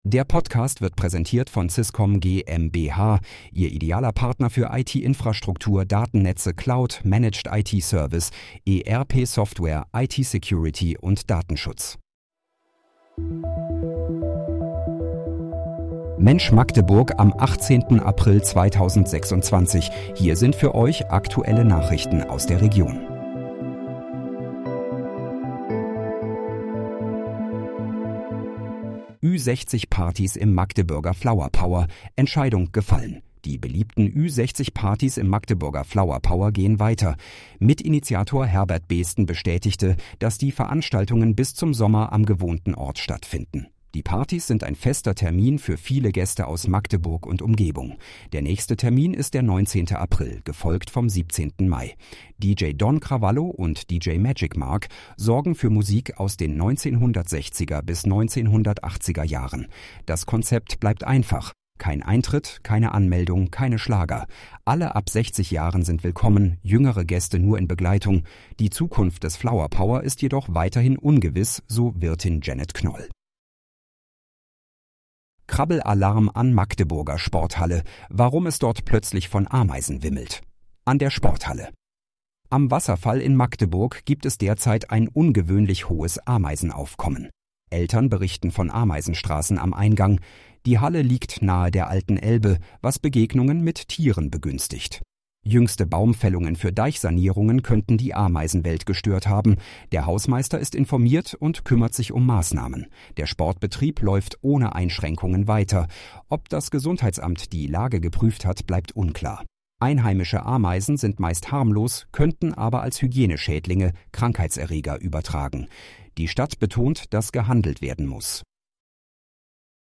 Mensch, Magdeburg: Aktuelle Nachrichten vom 18.04.2026, erstellt mit KI-Unterstützung